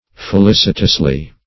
-- Fe*lic"i*tous*ly, adv. -- Fe*lic"i*tous*ness, n.